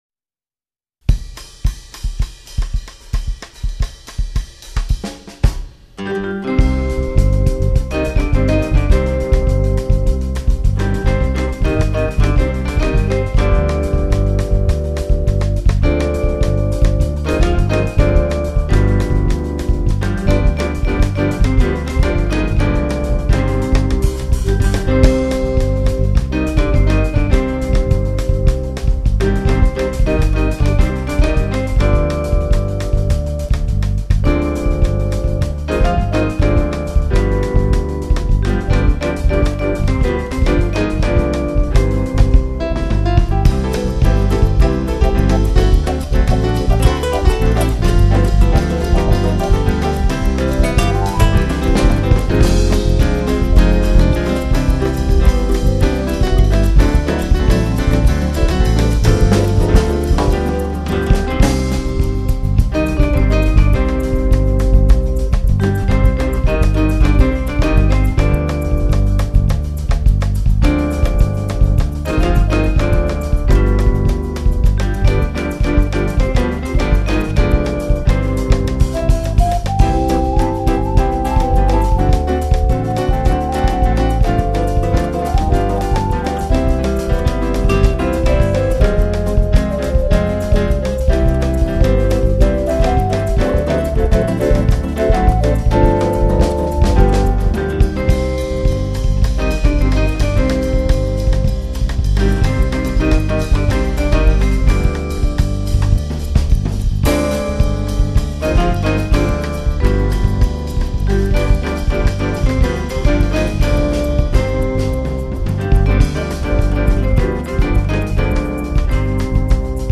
Gravado no Studio Max - Porto Alegre
Instrumento Utilizado : Teclado Roland E-16
Vibrafone, Acordeon e Piano
Contrabaixo
Guitarra
Bateria